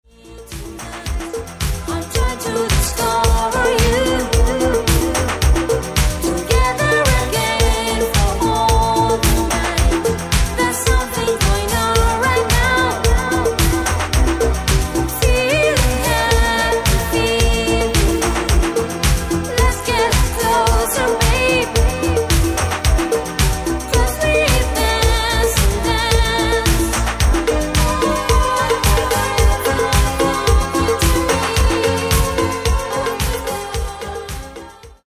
Genere:   Disco | Soul | Funk